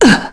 Tanya-Vox_Damage_kr_01.wav